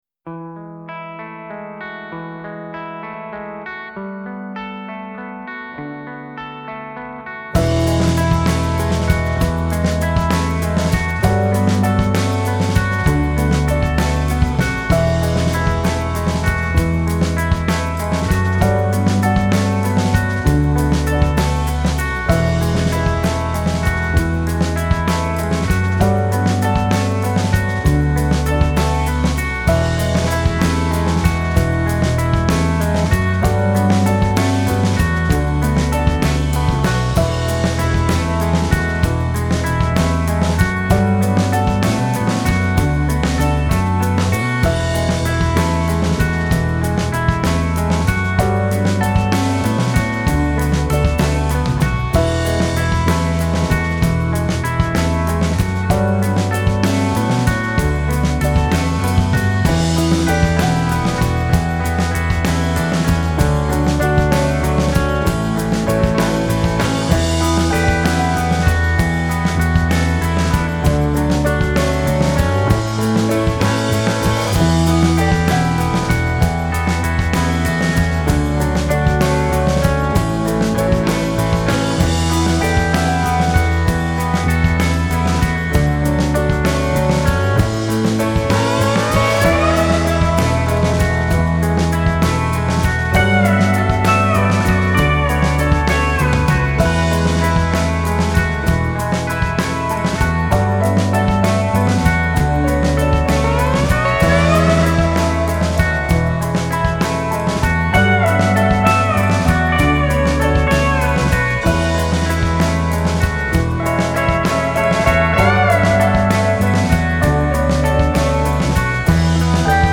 The gifted guitarist has performed in a handful of bands
instrumental ensemble
classically trained pianist
Loose, atmospheric – and yet clutter-free
reflective folk-rock.